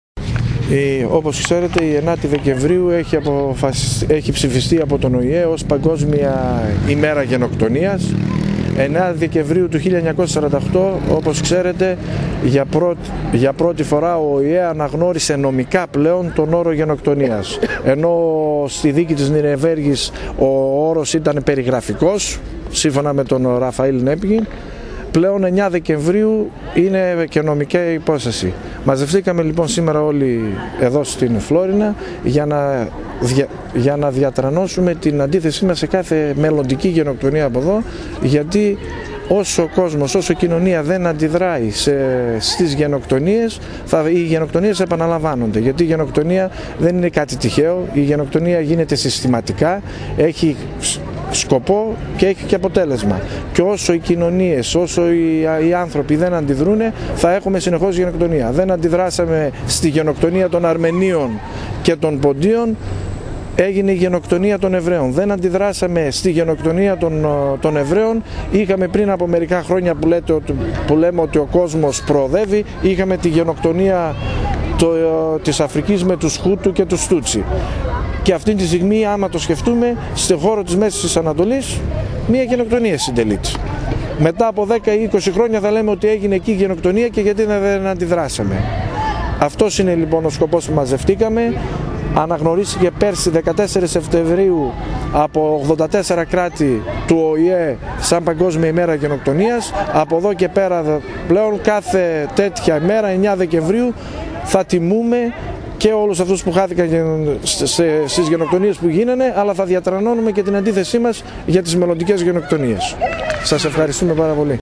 Ειρηνική διαμαρτυρία διοργάνωσε την Παρασκευή το απόγευμα στην κεντρική πλατεία της Φλώρινας, η Εύξεινος Λέσχη, με αφορμή την Παγκόσμια Ημέρα Γενοκτονίας.